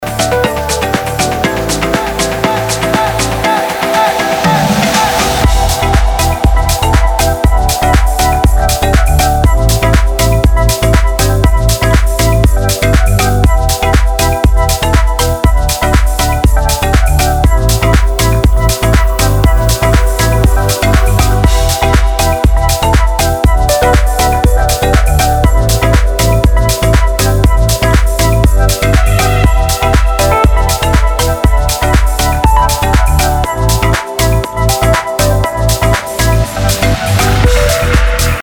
• Качество: 320, Stereo
ритмичные
deep house
спокойные
без слов
progressive house
Progressive House, Deep Techno 2018